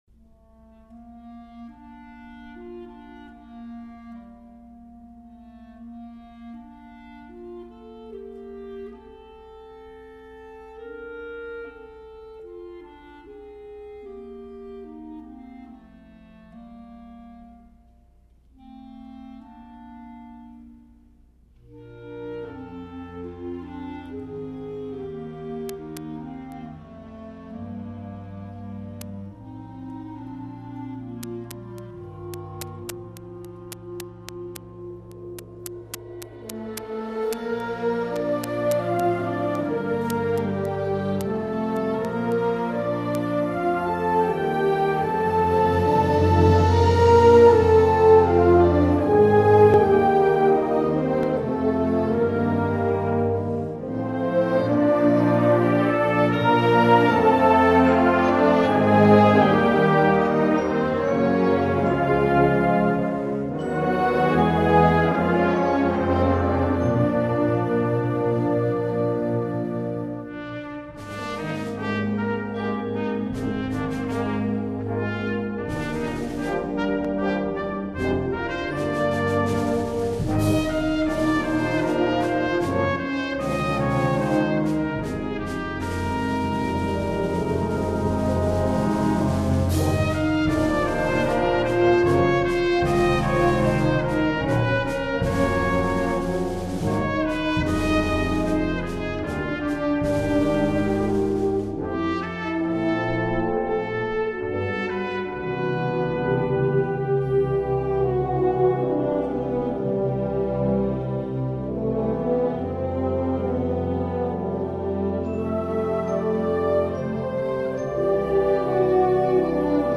Concert Band - Exhibition Series
Standard Concert Band Parts with Conductor Score